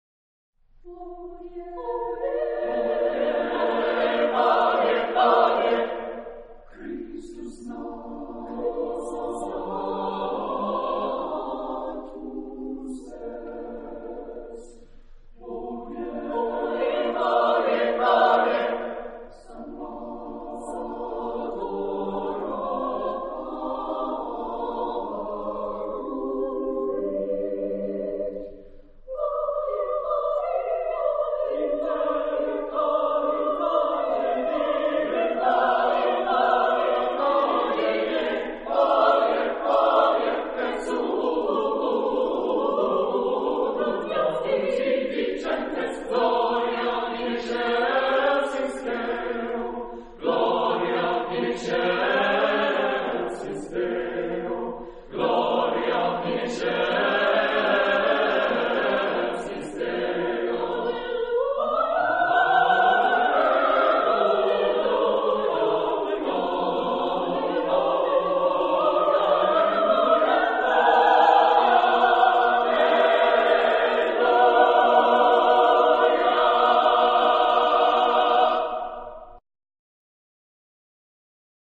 Anthem.